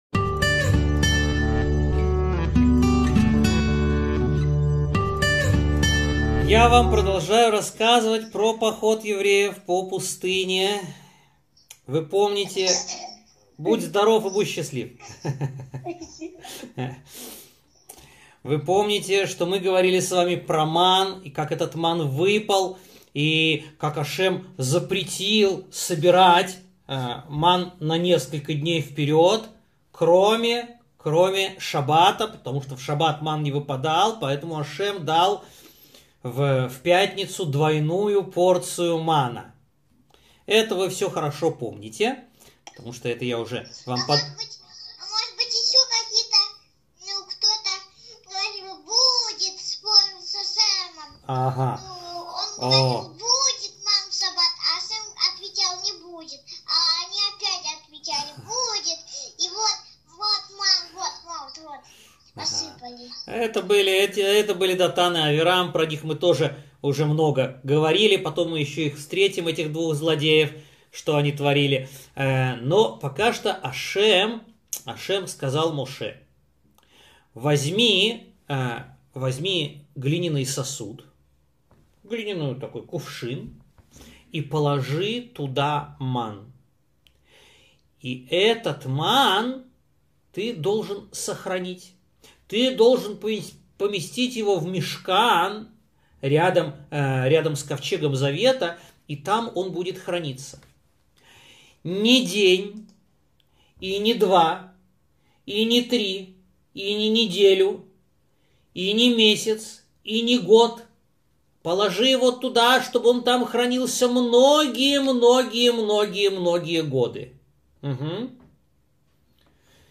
Маса у-мерива - 77 урок из серии "сипурей Тора"